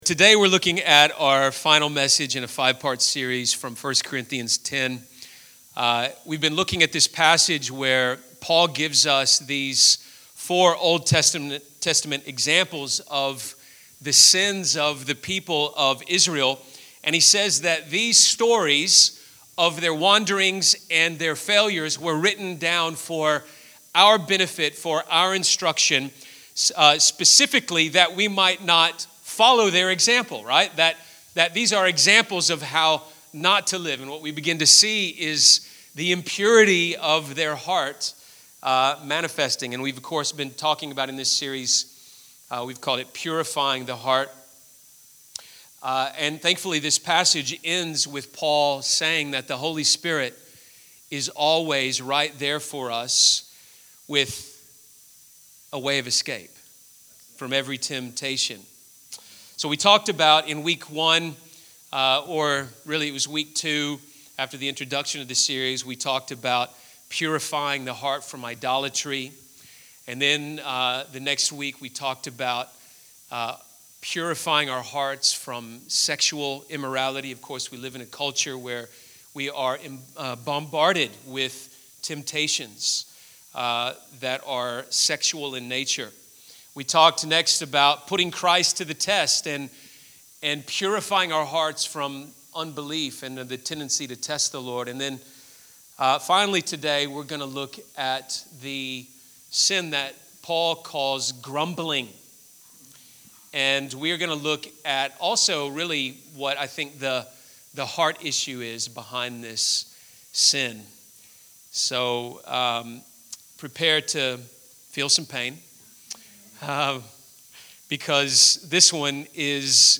This is the final message in a five-part series called, Purifying the Heart.